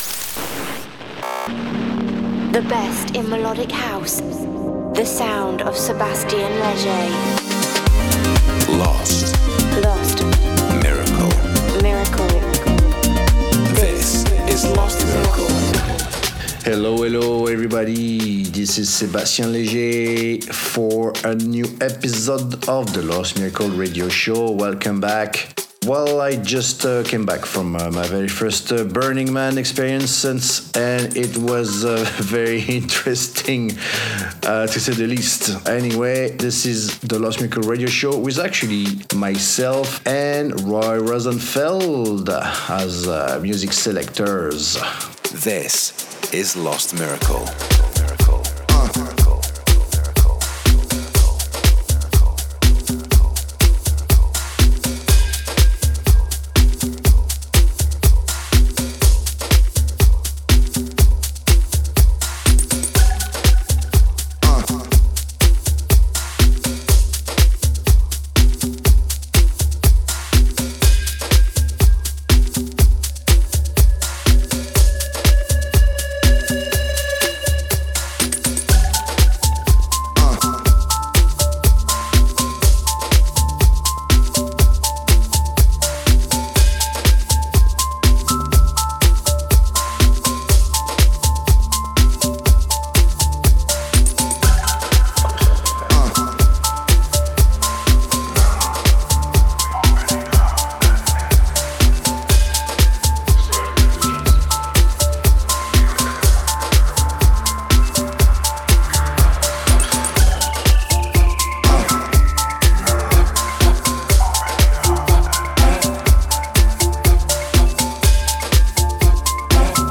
the monthly radio show